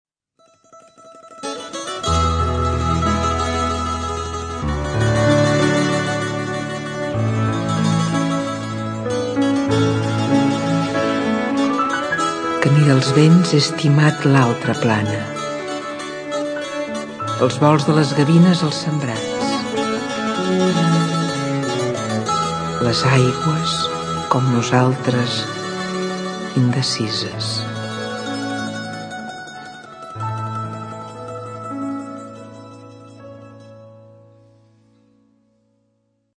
recitat